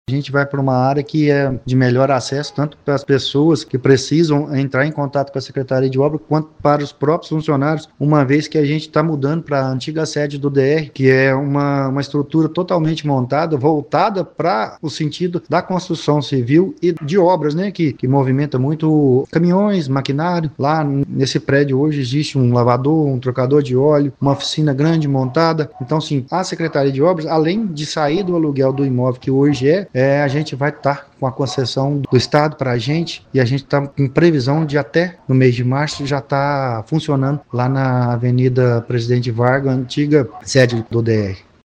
O secretário de Obras, André Lara Amaral, destaca que a migração para a Presidente Vargas foi pensada para gerar um impacto positivo direto na ponta, beneficiando tanto o cidadão que busca atendimento quanto o servidor que terá melhores condições de trabalho.